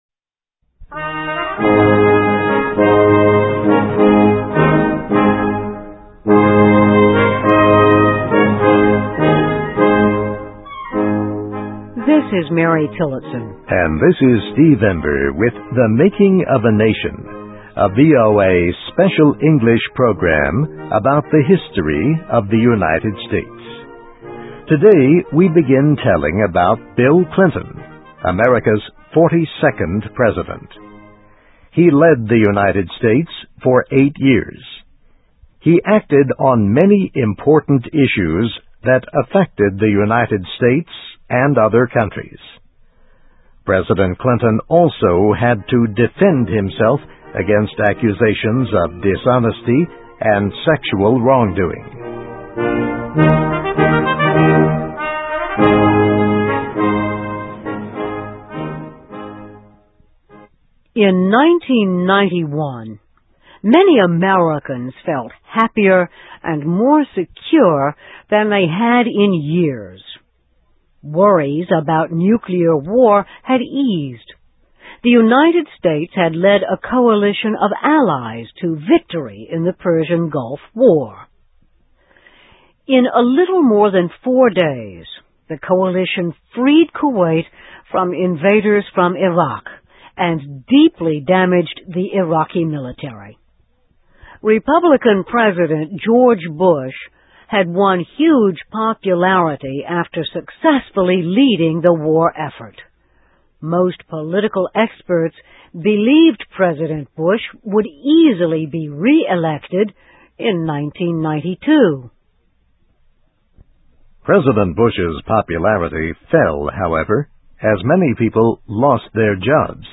American History: A 'Man From Hope' Is Elected to the White House in 1992 (VOA Special English 2007-07-25)
Listen and Read Along - Text with Audio - For ESL Students - For Learning English